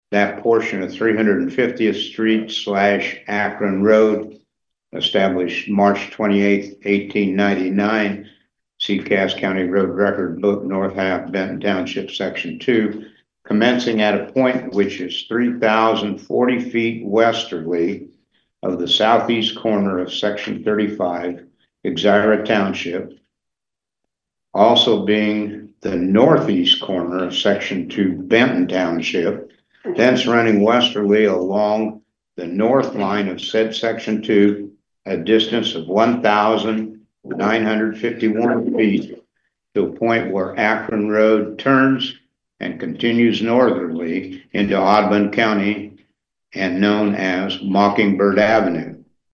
Cass County BOS mtg., 2-18-25